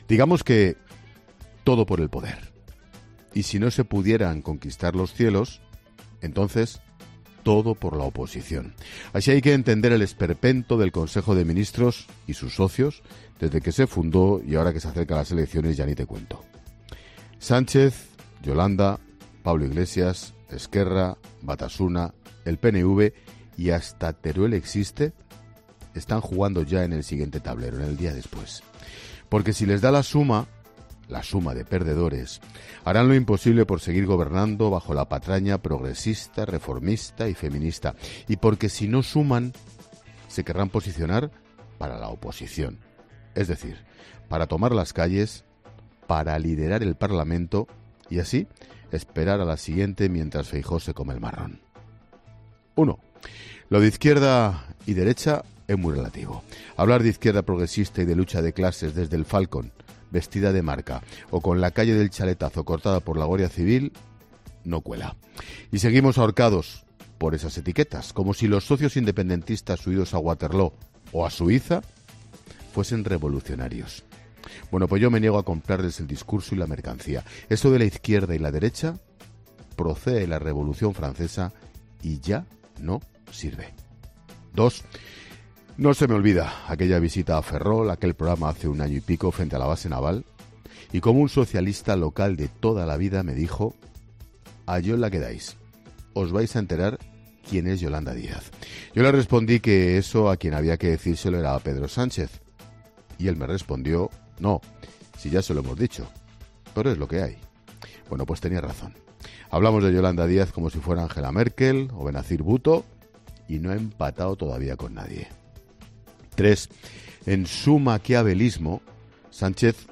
Así lo aseguraba este martes en su monólogo del programa de actualidad, en el que analizaba el fuego cruzado durante las últimas semanas entre los apoyos de la nueva plataforma de la ministra de Trabajo y los líderes de Podemos.